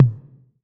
6TOM MID 1.wav